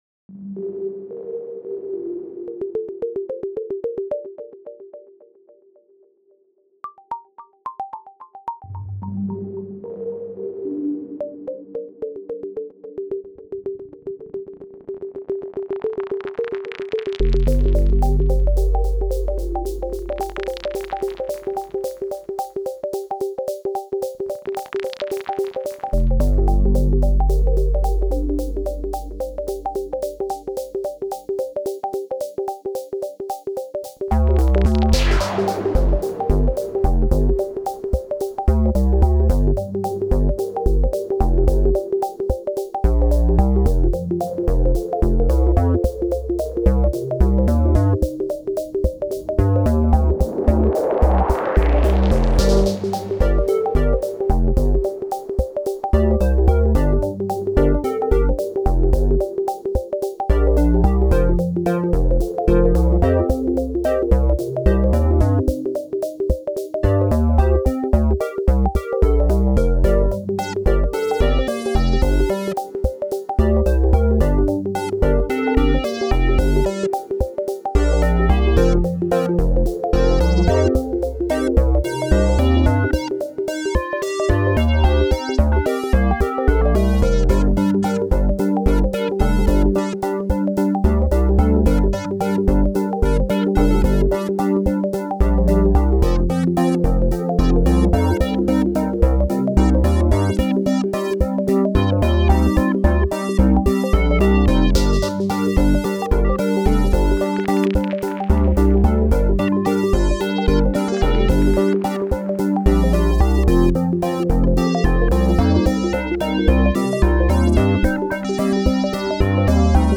a song that solely bases on the Klopfgeist plugin and the plugins integrated in Logic Audio 5.x